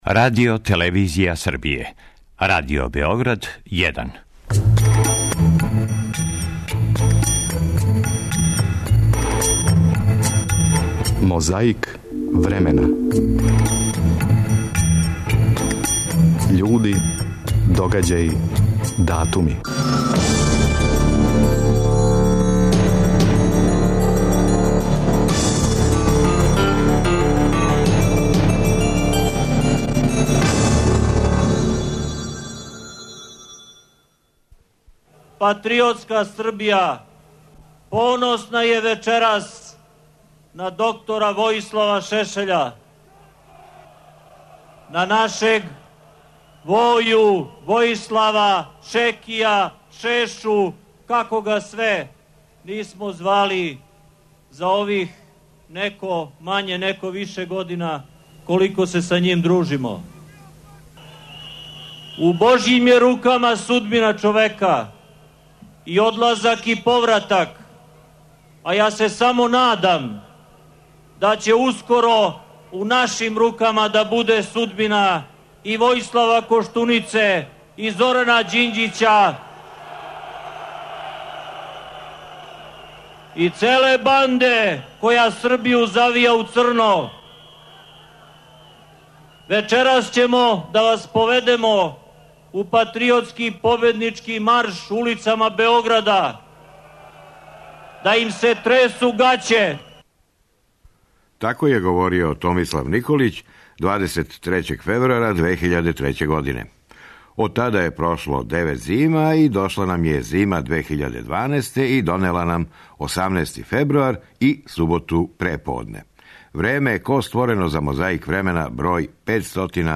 Почињемо звучном коцкицом из 2003. године када је говорио Томислав Николић.